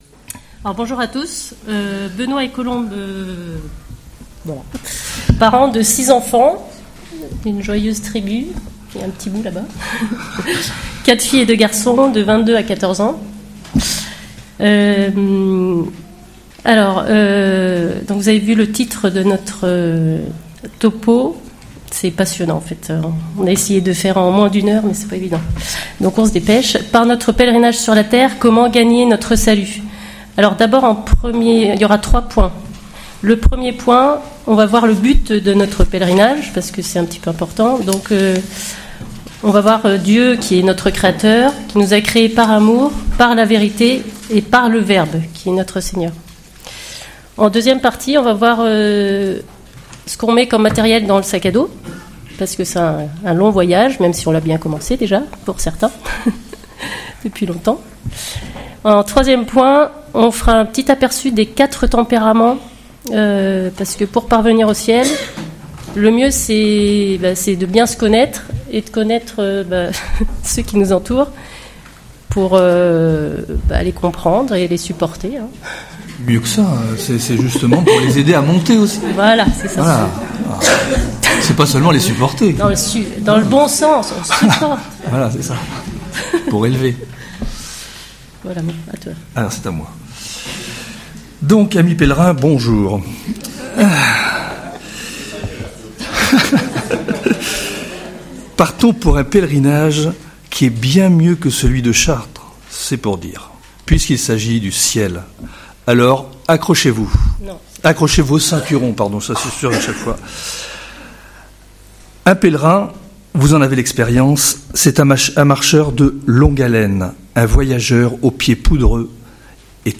Enseignement/témoignage